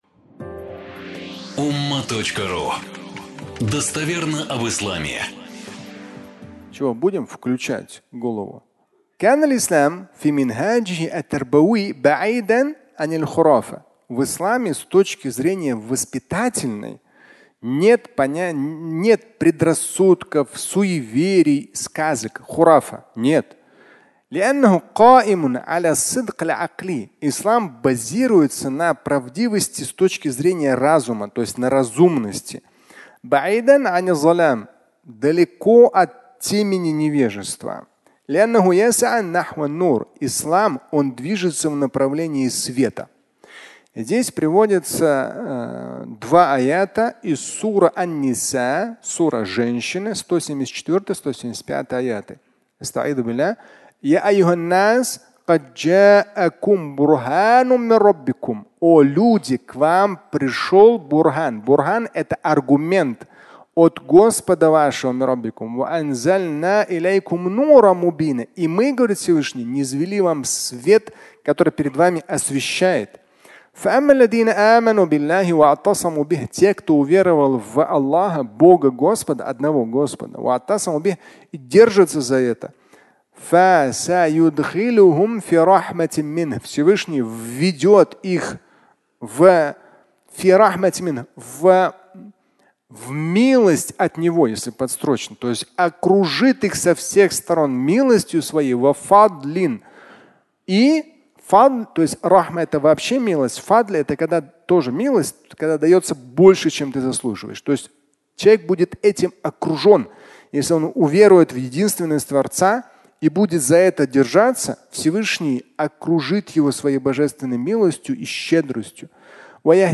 Включить мозги (аудиолекция)